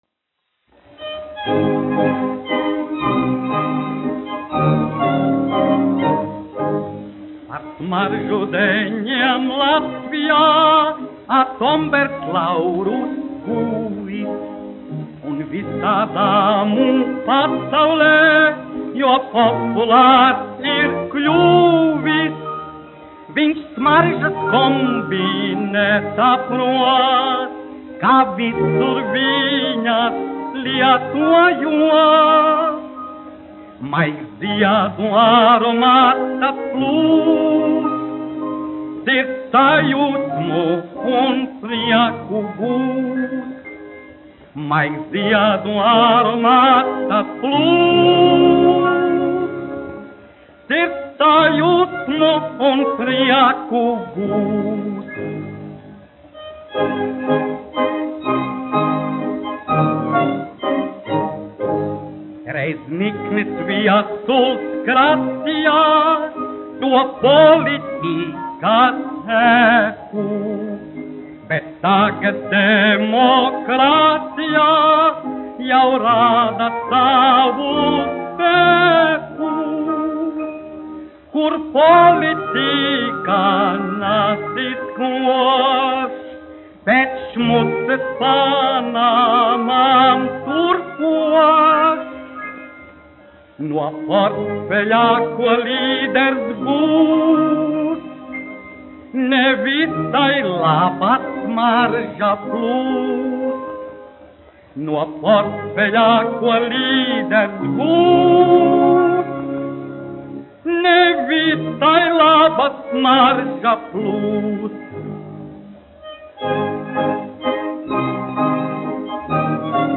1 skpl. : analogs, 78 apgr/min, mono ; 25 cm
Populārā mūzika
Humoristiskās dziesmas
Skaņuplate